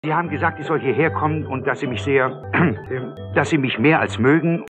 Hörprobe des deutschen Synchronschauspielers